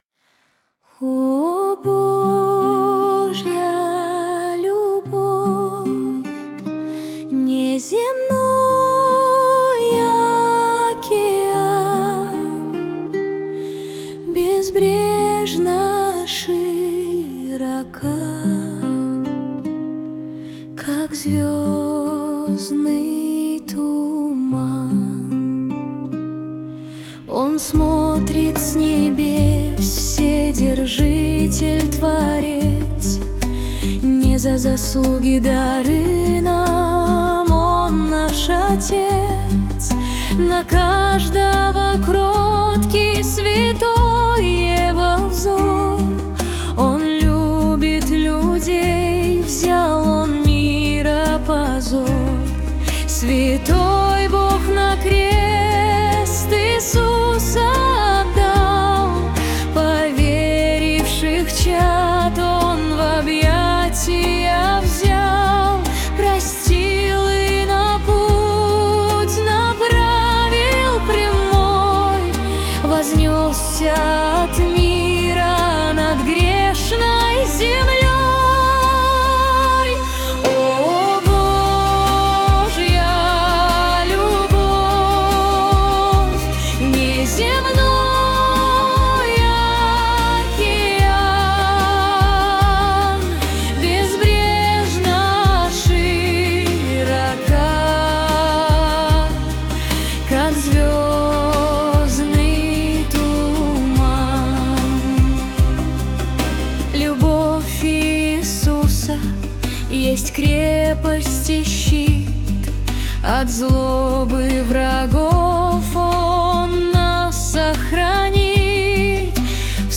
Нейросеть поёт Христу.
Представленные ниже песни были созданы с помощью нейронной сети на основе наших стихов